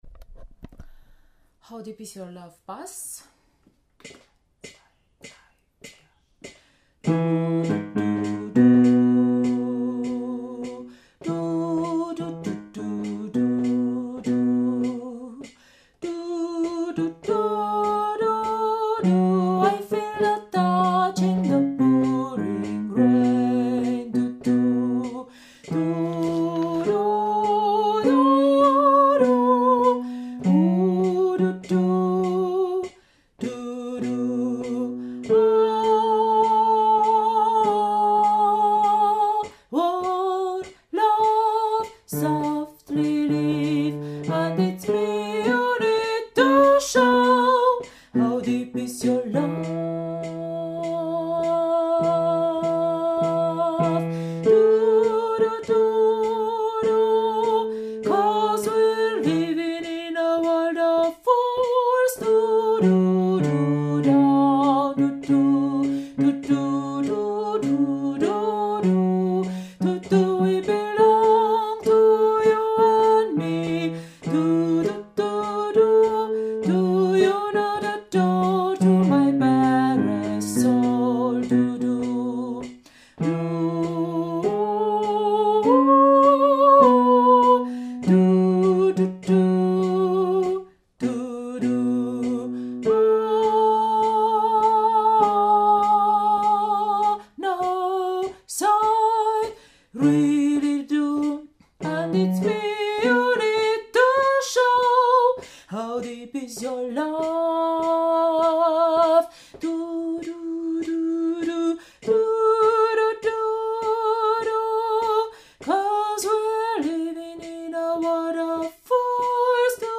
How deep is your love – Bass